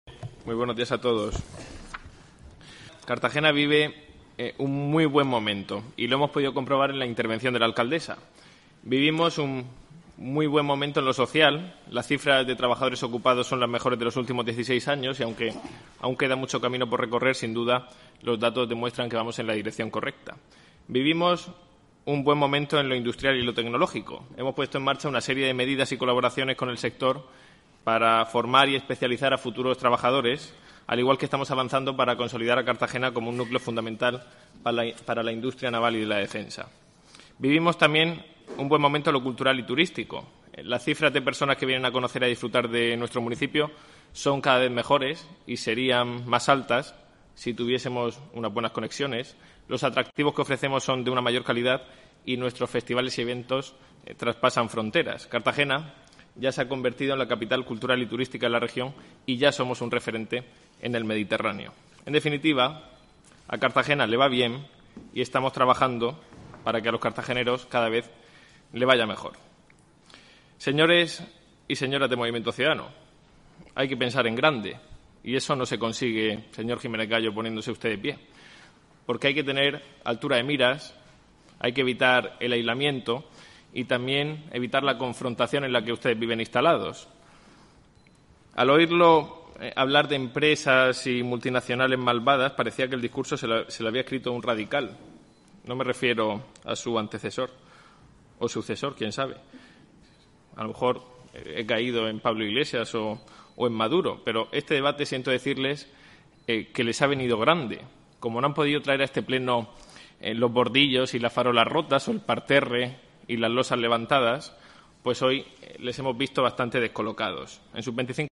Durante su intervención en el pleno del Debate sobre el Estado del Municipio, Jáudenes ha recordado que “en la legislatura pasada sentamos las bases de planes estratégicos como el de turismo, el de agenda urbana o el de movilidad”.